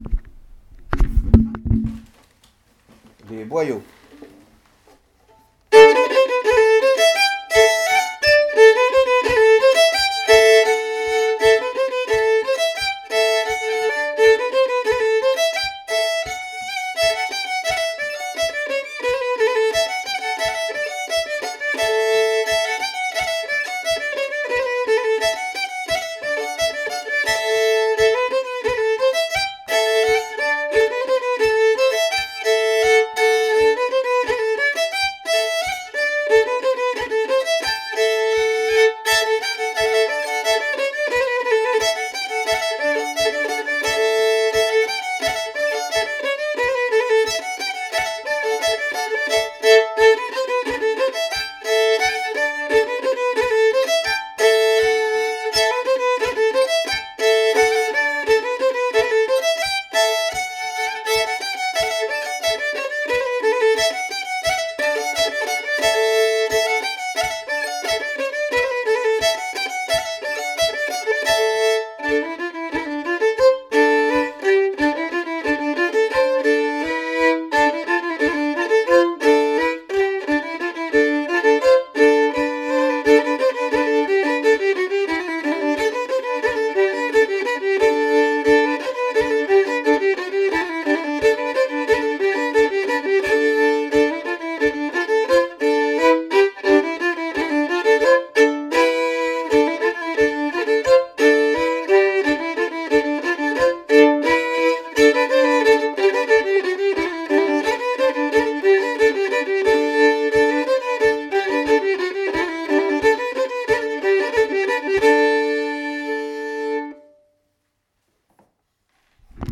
Musiques et danses traditionnelles en
"Tradivarium"  est constitué d'une quarantaine de  musiciens,  tous amateurs.